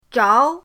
zhao2.mp3